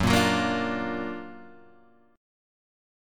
F#+M7 chord